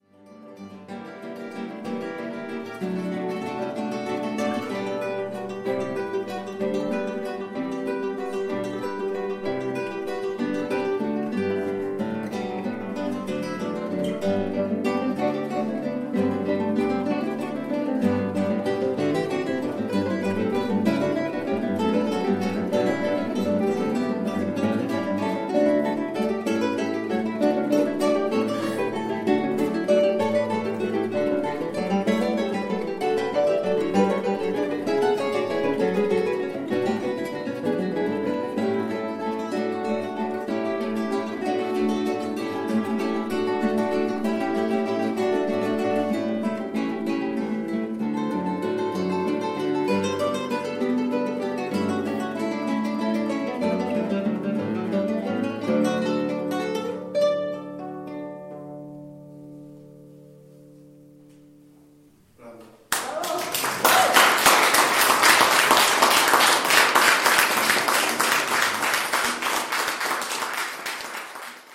Die Hörproben des Quartetts sind ungeschnitten und nicht nachbearbeitet, daher ist nicht mit Aufnahmen in Studio-Qualität zu rechnen.
Die Stücke sind aus verschiedenen Epochen und Stilen ausgewählt, um die Vielseitigkeit des fantastischen Instruments Konzertgitarre zu zeigen.
J. S. Bach, Brandenburgisches Konzert Nr. 3, 3. Satz - Allegro [LIVE!]J. S. Bach, Brandenburgisches Konzert Nr. 3, 3.